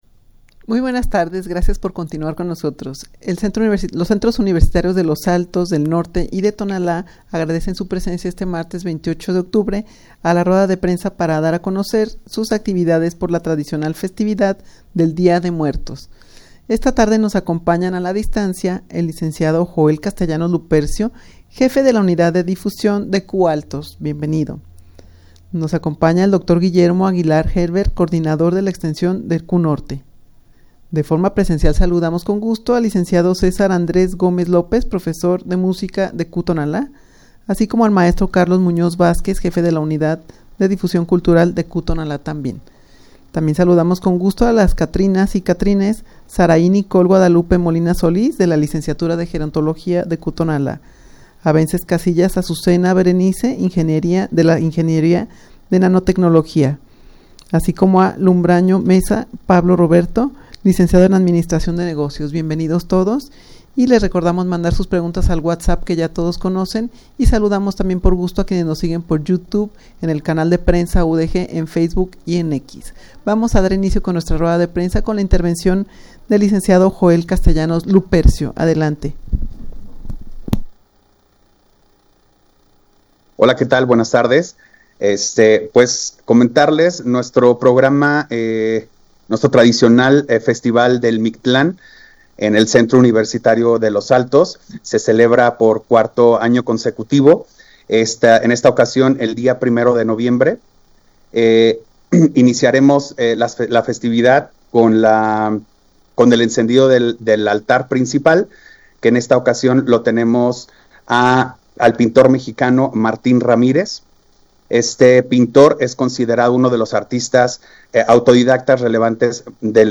rueda-de-prensa-para-dar-a-conocer-sus-actividades-por-la-tradicional-festividad-del-dia-de-muertos.mp3